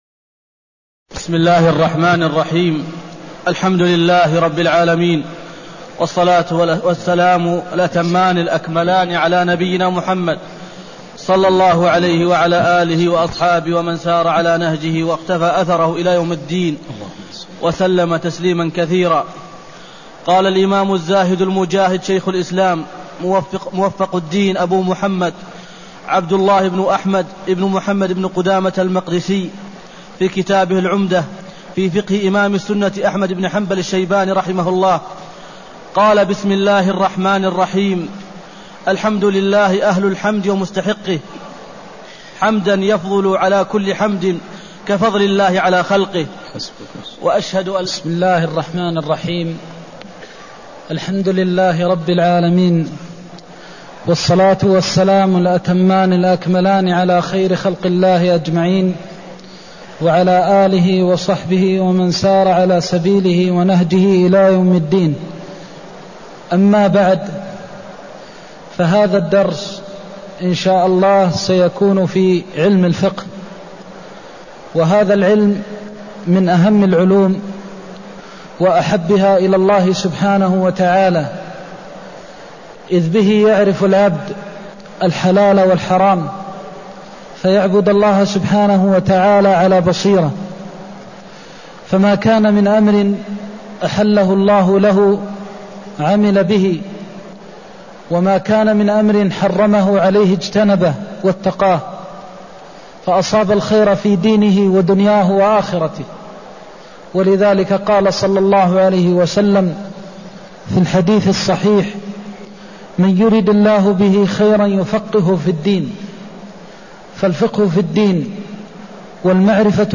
المكان: المسجد النبوي الشيخ: فضيلة الشيخ د. محمد بن محمد المختار فضيلة الشيخ د. محمد بن محمد المختار مقدمة الكتاب (01)